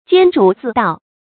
監主自盜 注音： ㄐㄧㄢ ㄓㄨˇ ㄗㄧˋ ㄉㄠˋ 讀音讀法： 意思解釋： 竊取公務上自己看管的財物。